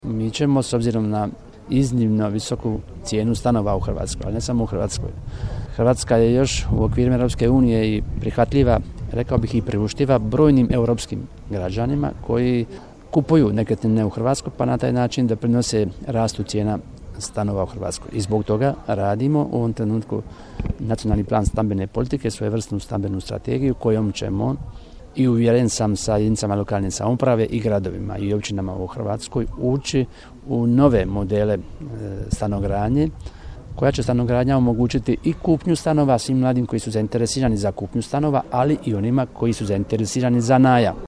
Stambena politika u srcu je svih politika hrvatske Vlade koja drži i da je ista preduvjet opstanka mladih, rekao je Bačić, dodajući da se razmatraju novi modeli stanogradnje: